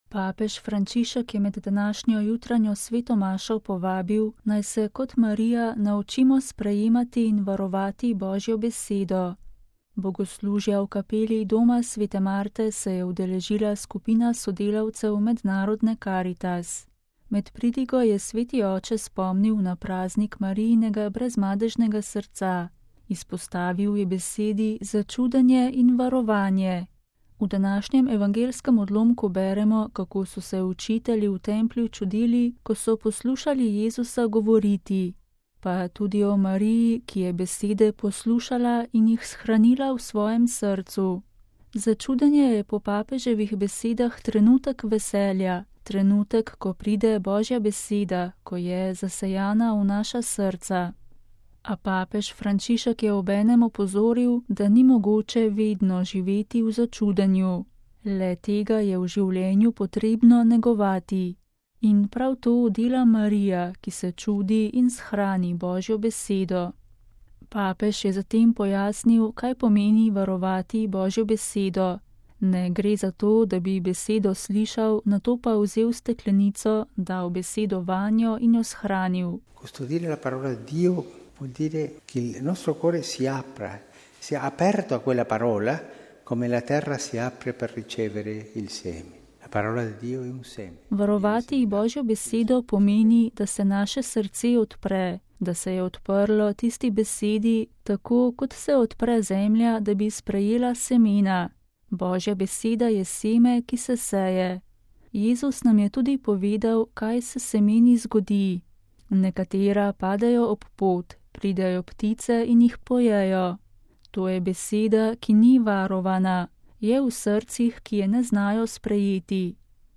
VATIKAN (sobota, 8. junij 2013, RV) – Papež Frančišek je med današnjo jutranjo sveto mašo povabil, naj se kot Marija naučimo sprejemati in varovati Božjo besedo. Bogoslužja v kapeli Doma sv. Marte se je udeležila skupina sodelavcev Mednarodne Karitas.